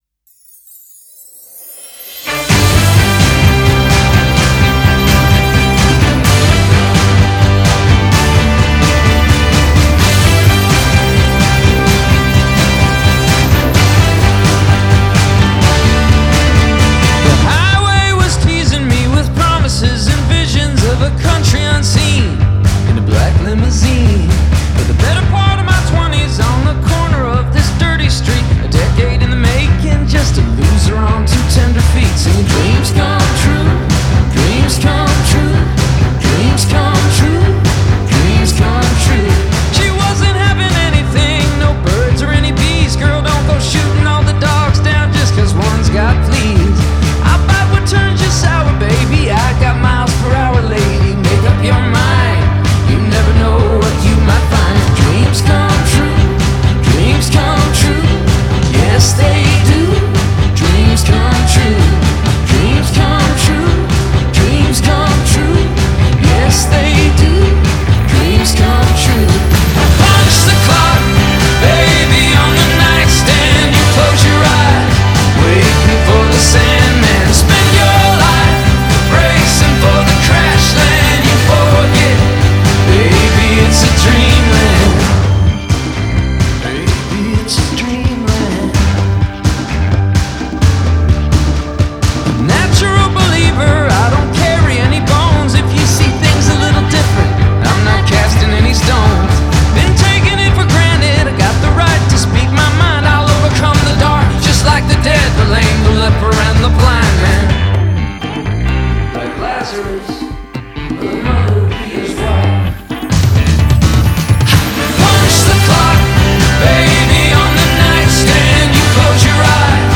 Genre: Pop Rock, Indie Rock